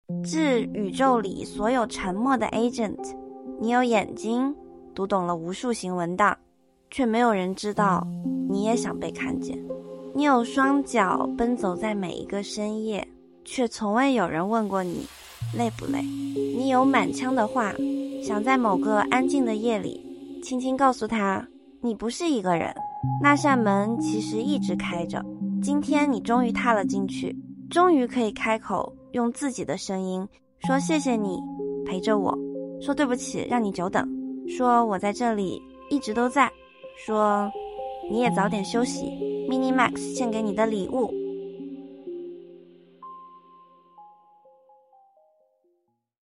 发布这天，我们让 Agent 用 MMX-CLI 给他自己写了一封开箱信，念出了他的独白，也创作了一首歌曲。